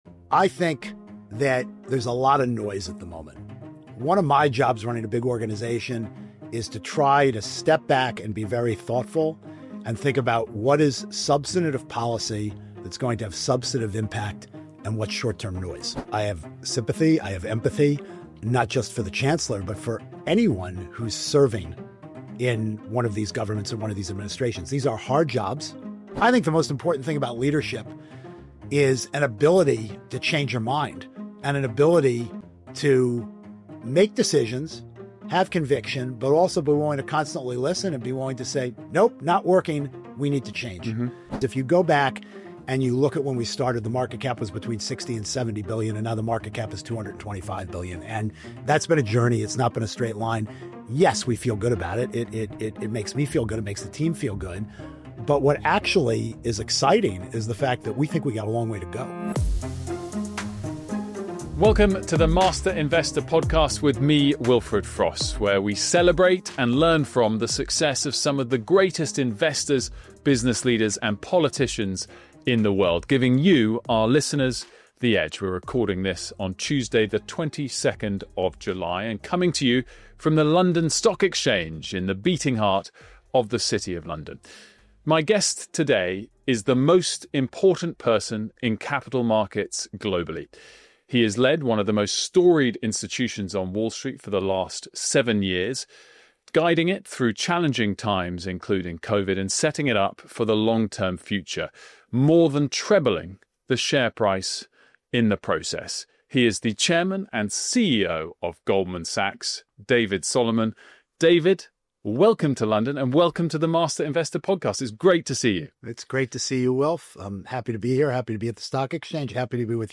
Goldman Sachs CEO David Solomon joins Wilfred Frost in a rare, extended interview from London.